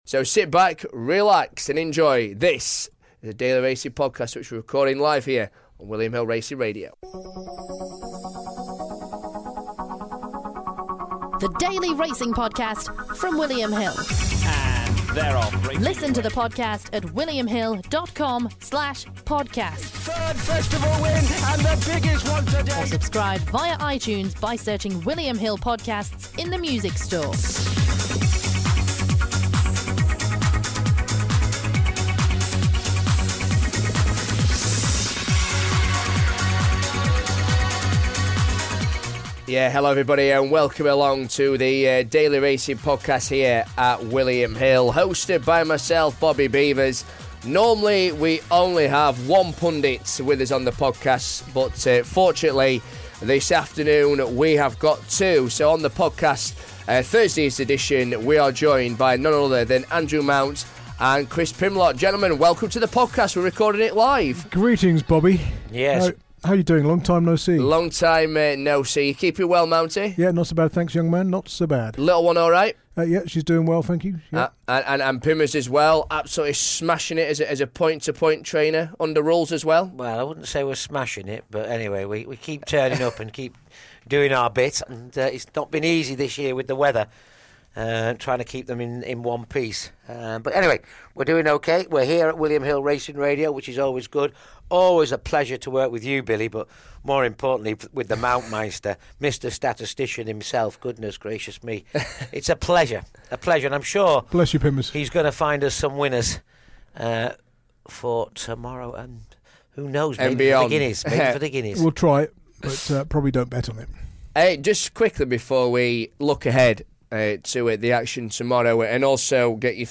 racing experts